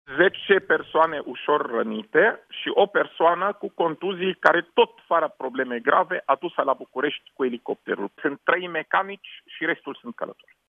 Șeful Departamentului pentru Situații de Urgență, Raed Arafat:
18dec-18-Arafat-despre-victime.mp3